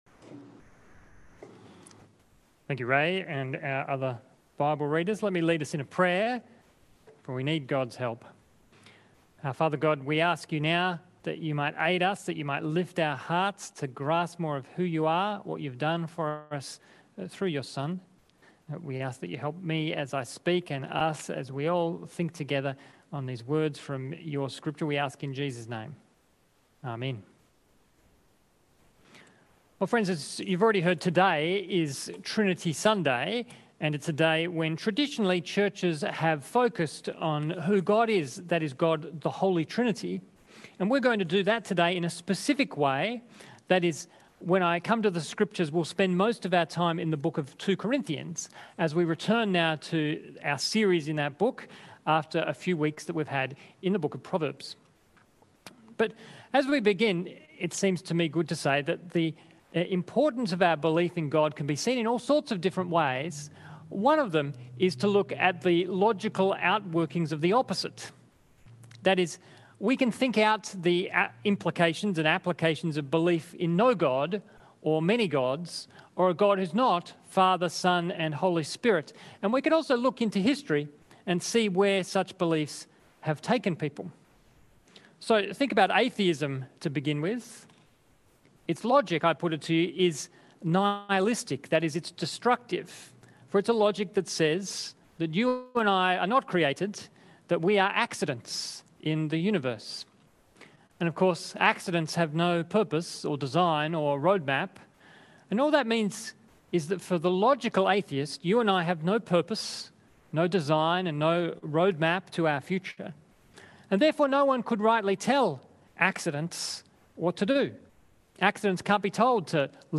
Sermons | St George's Magill Anglican Church